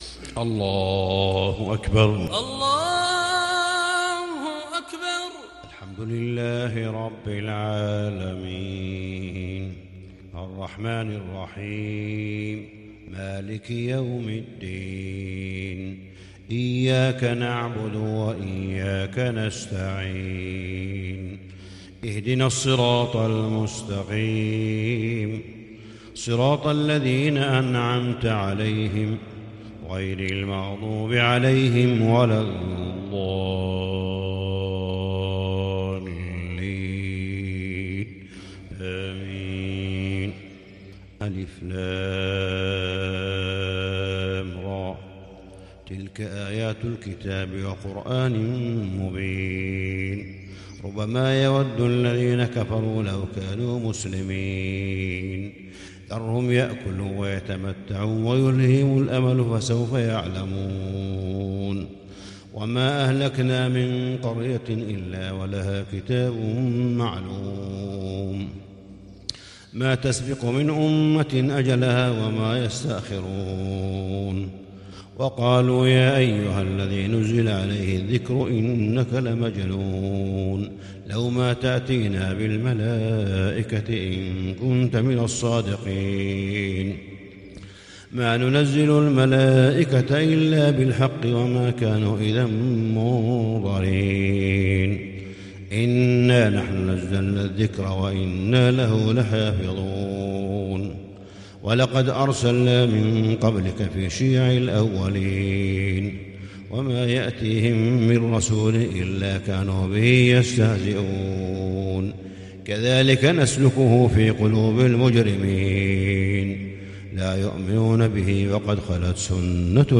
صلاة الفجر للشيخ صالح بن حميد 20 جمادي الأول 1441 هـ
تِلَاوَات الْحَرَمَيْن .